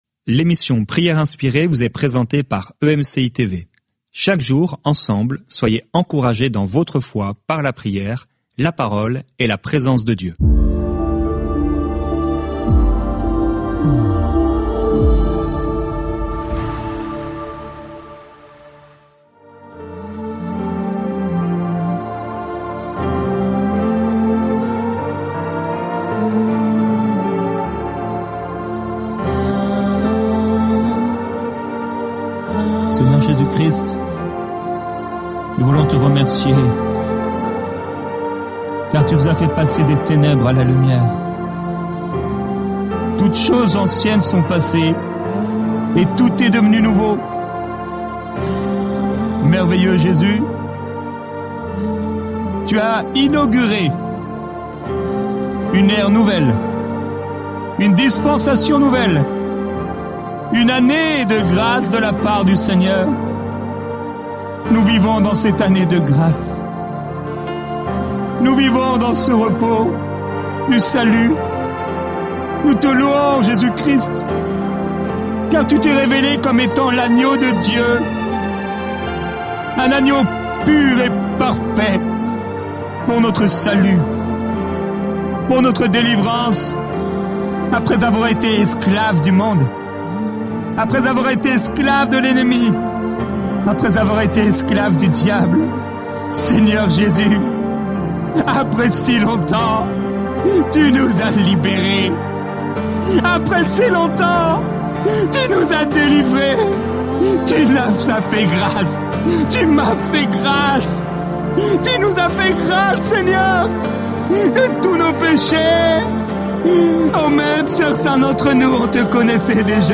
Au programme, une pensée du jour, un temps de louange, l'encouragement du jour et un temps de prière et de déclaration prophétique.